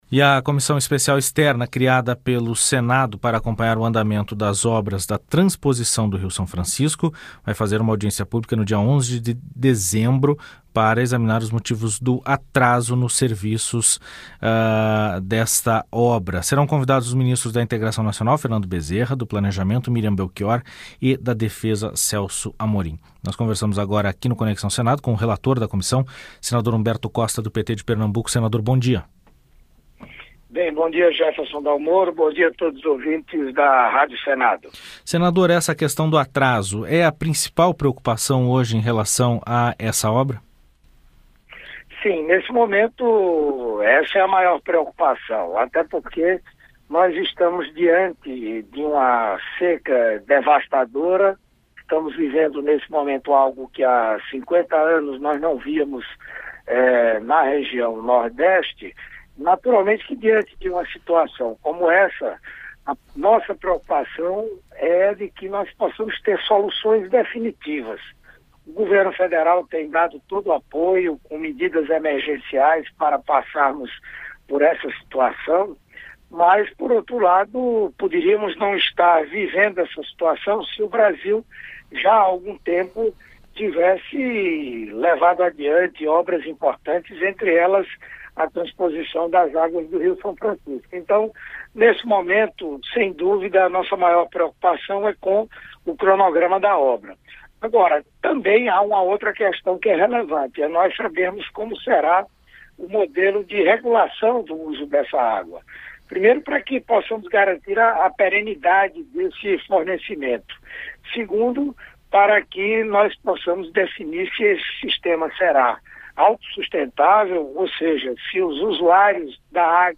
Entrevista com o senador Humberto Costa (PT-PE).